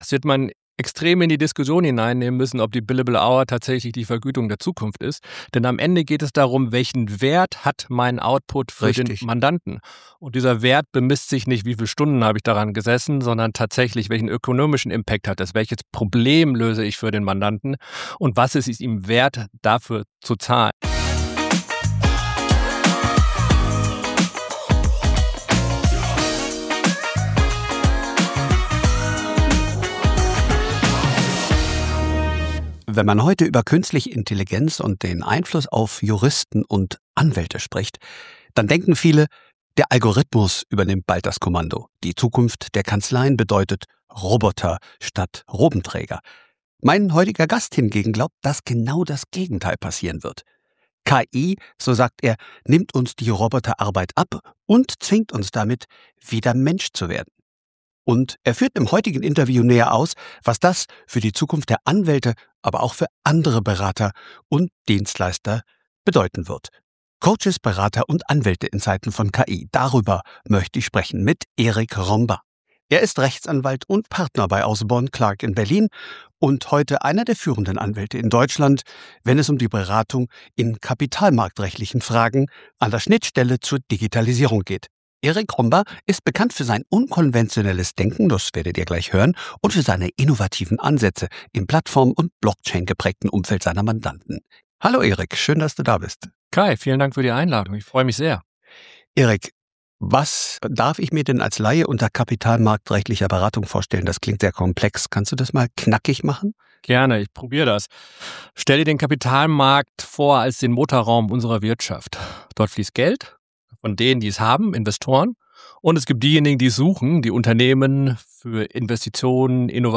Im Interview stellen wir Thesen auf, was sich bei der Ausbildung zukünftiger Juristinnen und Juristen ändern muss.